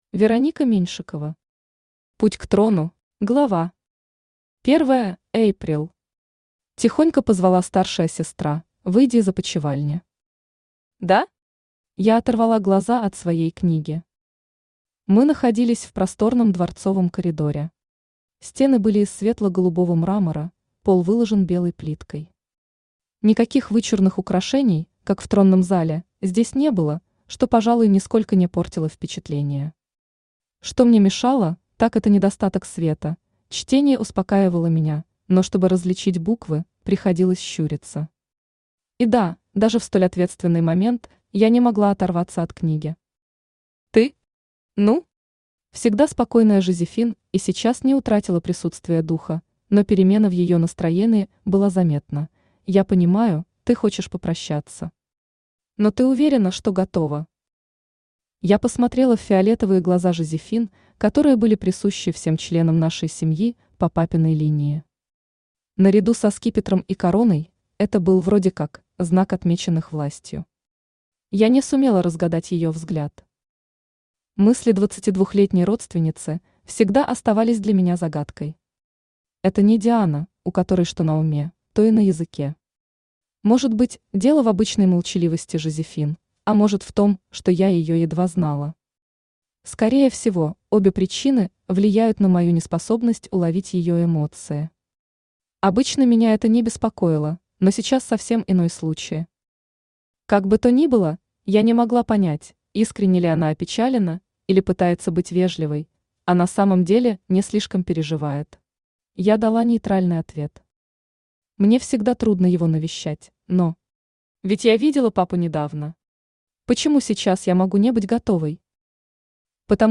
Аудиокнига Путь к трону | Библиотека аудиокниг
Aудиокнига Путь к трону Автор Вероника Сергеевна Меньшикова Читает аудиокнигу Авточтец ЛитРес.